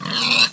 sounds / mob / pig / death.ogg